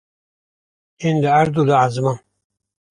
Pronunciado como (IPA)
/ʕɛzˈmɑːn/